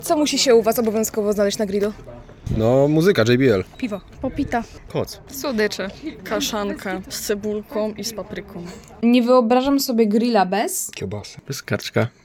Z okazji nieformalnego, ale coraz bardziej celebrowanego święta grilla postanowiliśmy zapytać studentów, co najczęściej ląduje na ruszcie.